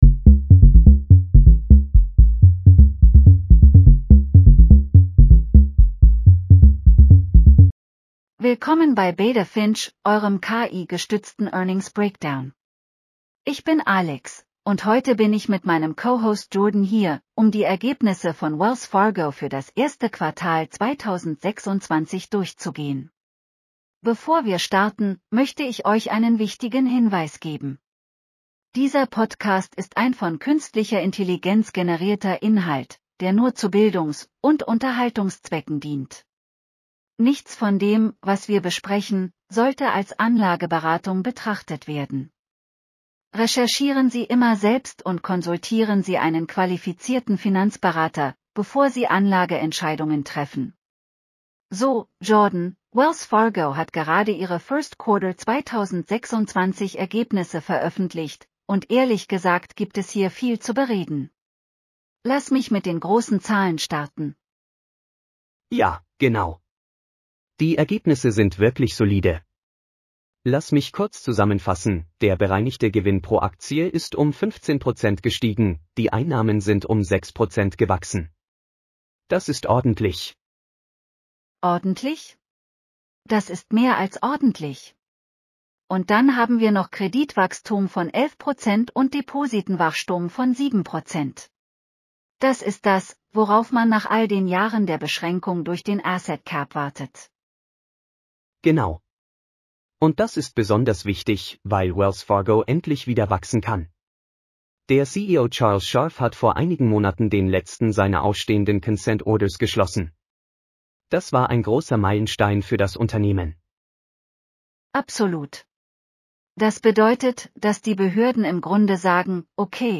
Willkommen bei Beta Finch, eurem KI-gestützten Earnings Breakdown.